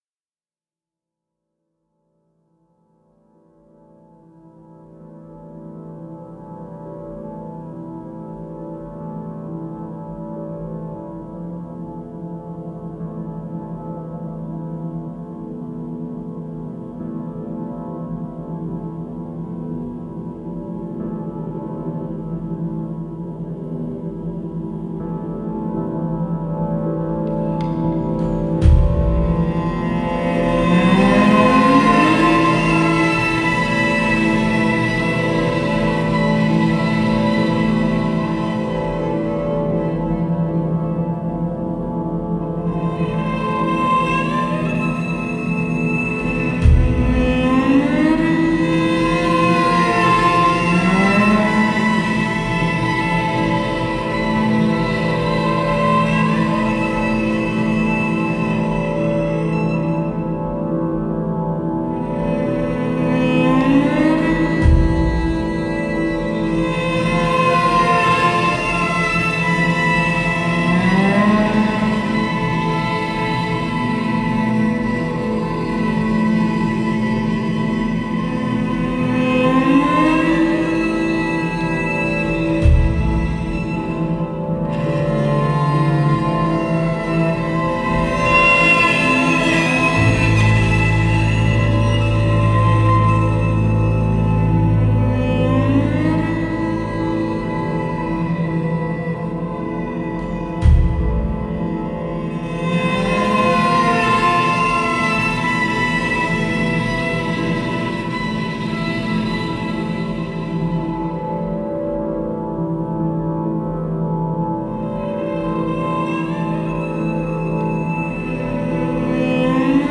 Genre: Ambient, New Age.